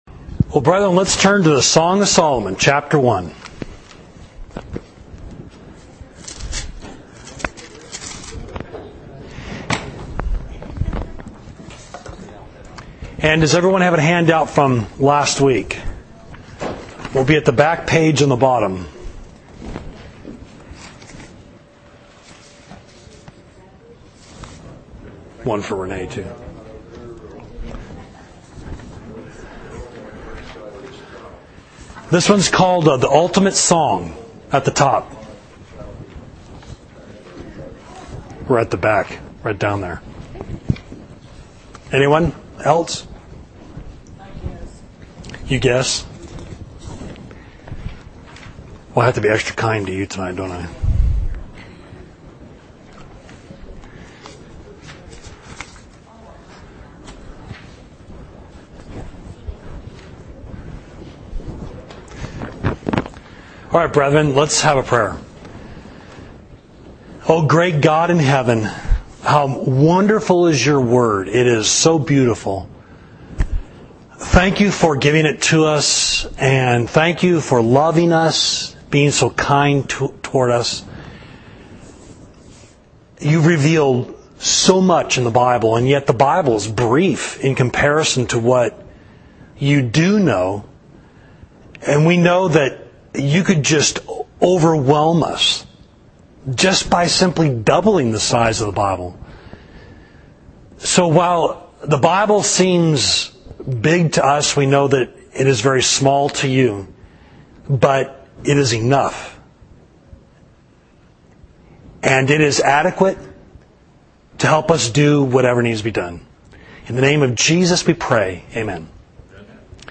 Class: Song of Solomon, August 8, AD 2012